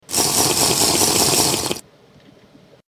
Category: Video Game Ringtones